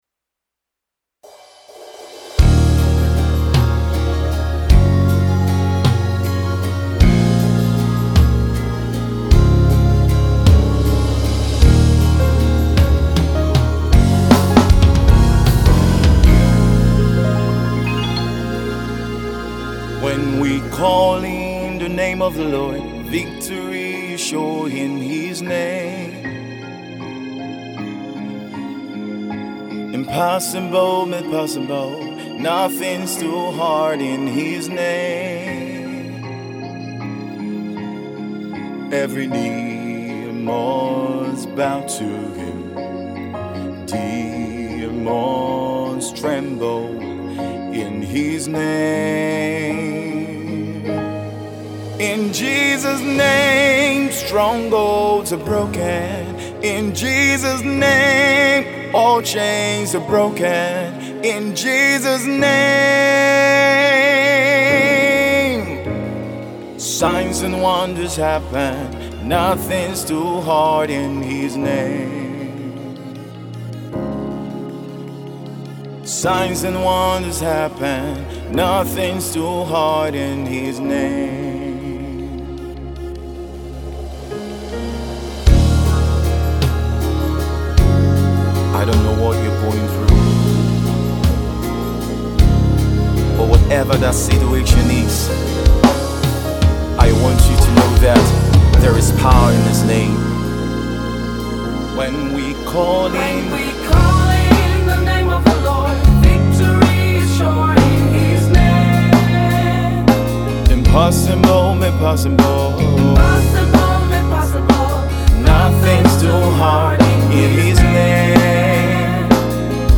Emerging Gospel artist